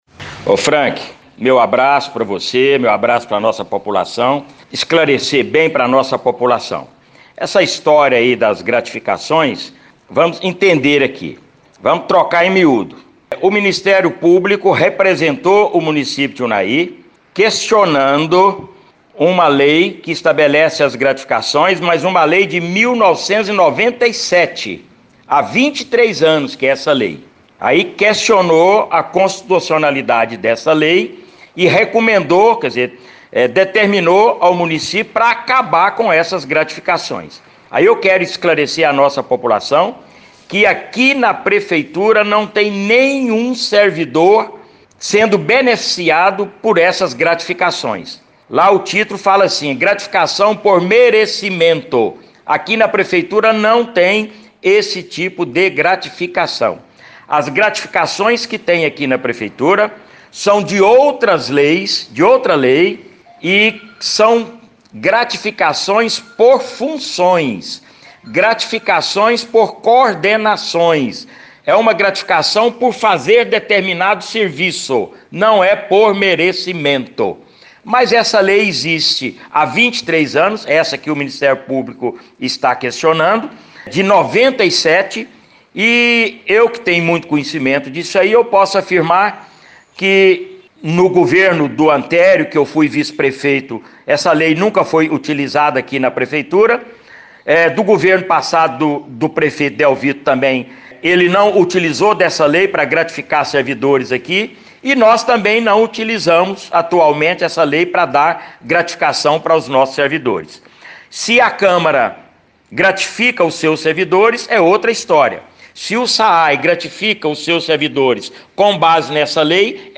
Procurada pela reportagem da Rádio Veredas, a PMU se manifestou através do prefeito Jose Gomes Branquinho. De acordo com ele, as gratificações concedidas aos servidores são por função, por coordenação e não por merecimento.